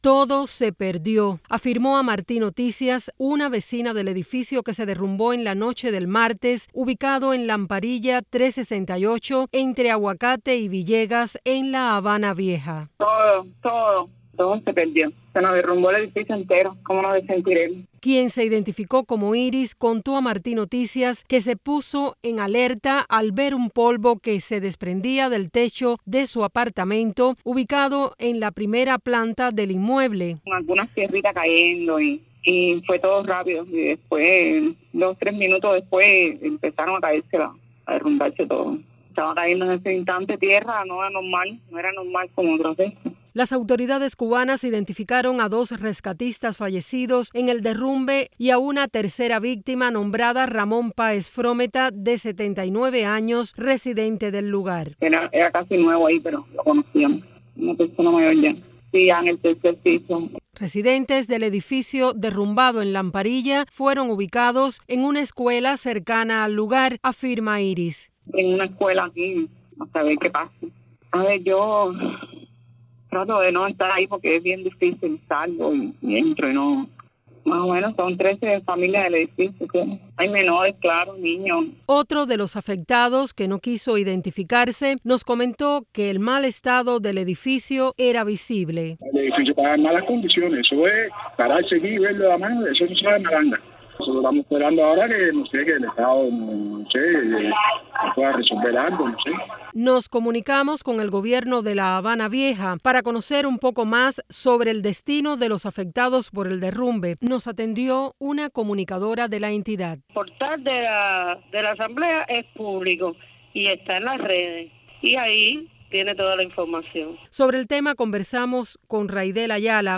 Residentes del edificio derrumbado en Habana Vieja hablan con Martí Noticias